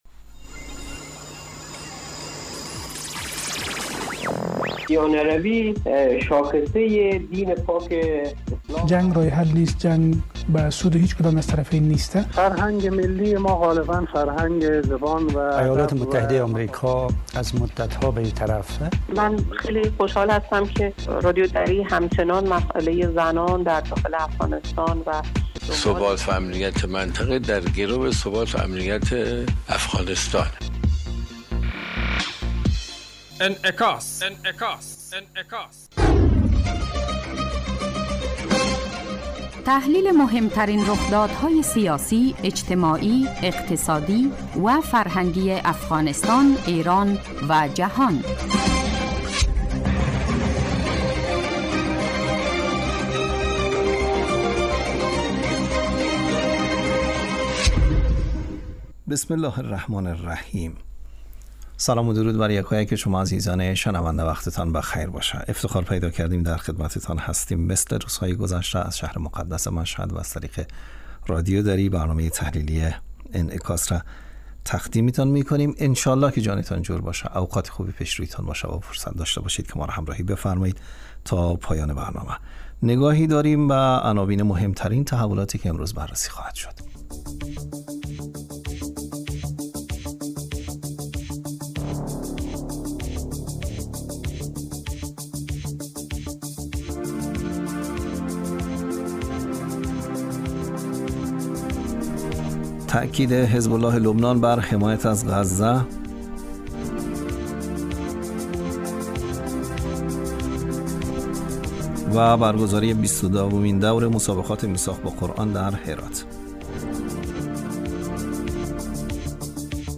برنامه انعکاس به مدت 30 دقیقه هر روز در ساعت 12:30 ظهر (به وقت افغانستان) بصورت زنده پخش می شود.